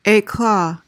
PRONUNCIATION: (ay-KLAH) MEANING: noun: 1.